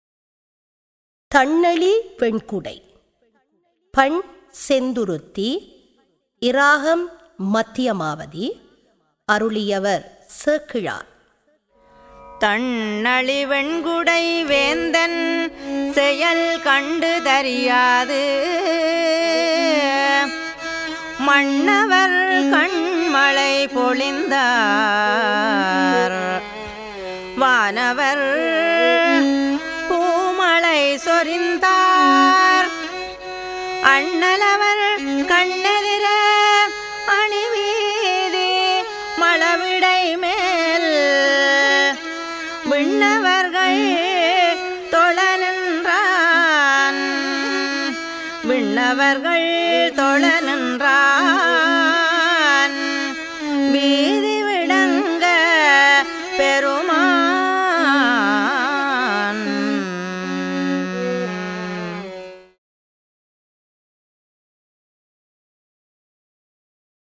தரம் 10 - சைவநெறி - அனைத்து தேவாரங்களின் தொகுப்பு - இசைவடிவில்